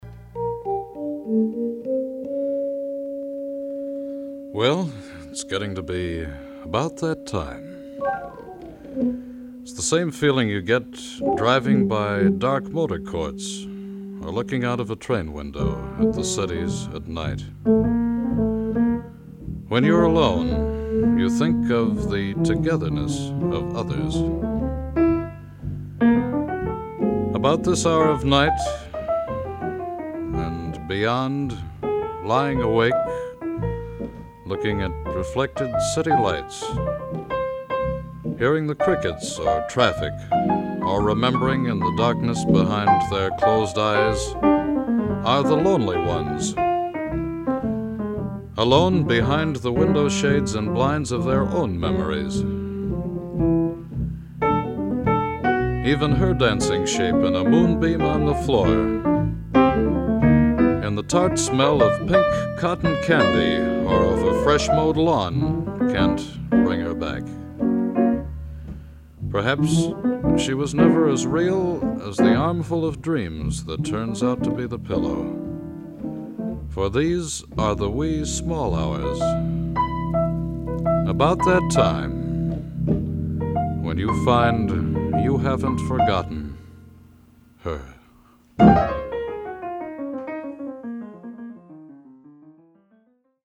evocative writing style and commanding vocal gift